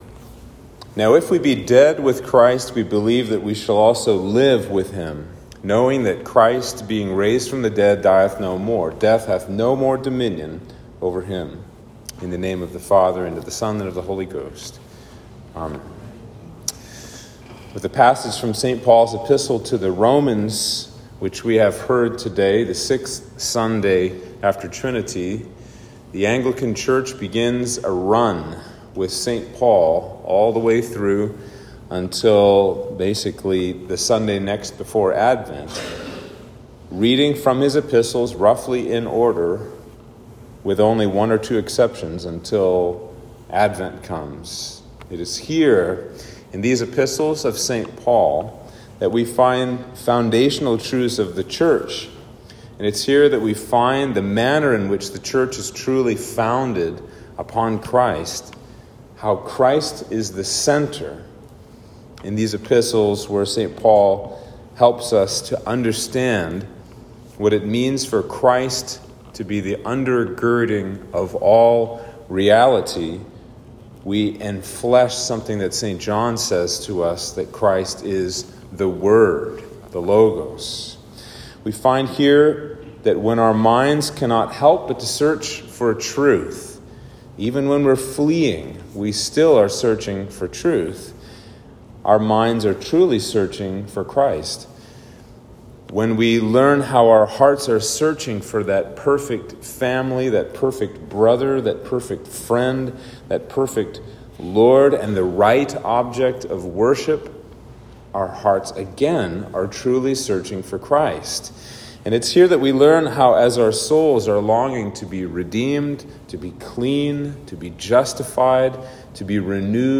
Sermon for Trinity 6